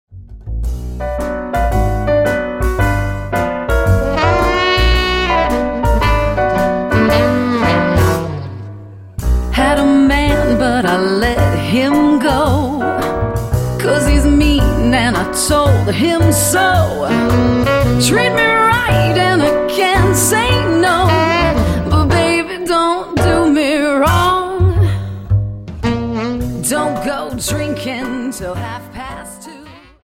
Dance: Slowfox 28